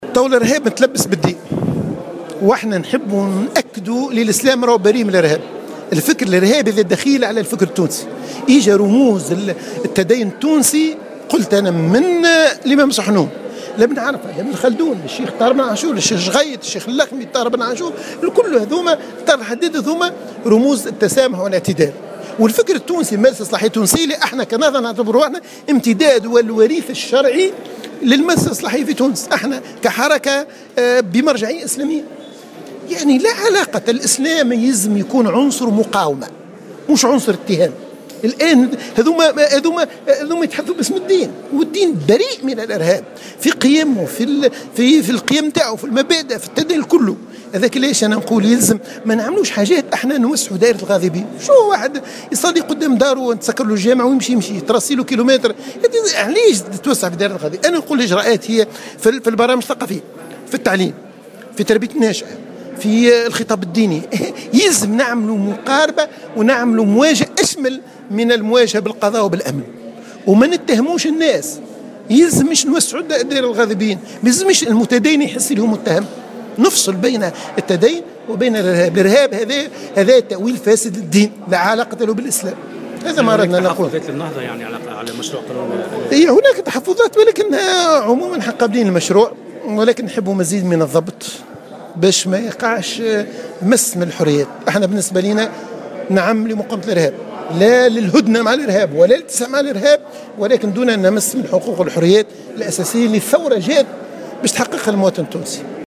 قال النائب في مجلس نواب الشعب عن حركة النهضة، الصحبي عتيڨ في تصريح لمراسل الجوهرة أف أم اليوم الاربعاء على هامش انطلاق أشغال مناقشة قانون مكافحة الإرهاب إن الفكر الإرهابي دخيل على الفكر التونسي، وأن لا علاقة بين هذه الظاهرة والإسلام، معتبرا أن بعض القرارات مثل غلق المساجد ستتسبب في تغذية الإرهاب وتوسيع دائرة الغاضبين.